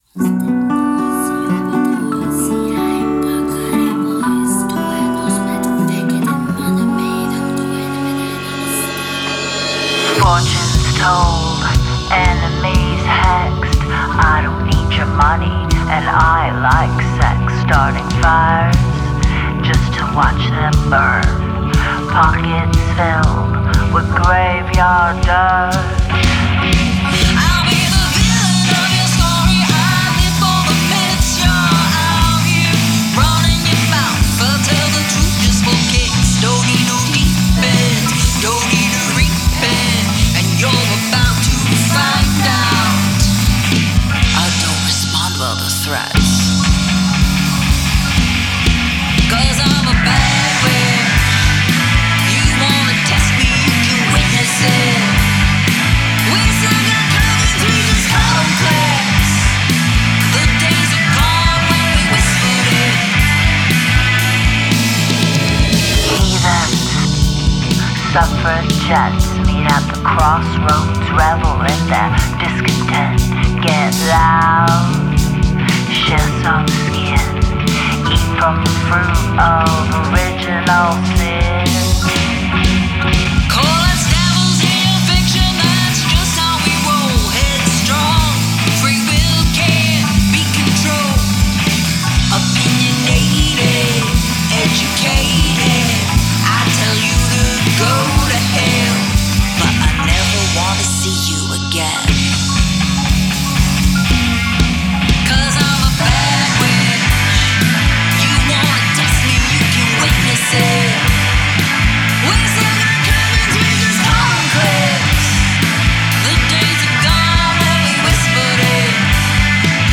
Guitar solo
Drums/percussive soundscapes
Guitars, organ, & sound effects
Key: Bm | BPM: 120, 4/4 time